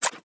Tab Select 6.wav